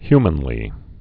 (hymən-lē)